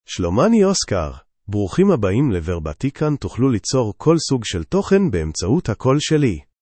Oscar — Male Hebrew (Israel) AI Voice | TTS, Voice Cloning & Video | Verbatik AI
Oscar is a male AI voice for Hebrew (Israel).
Voice sample
Listen to Oscar's male Hebrew voice.
Male
Oscar delivers clear pronunciation with authentic Israel Hebrew intonation, making your content sound professionally produced.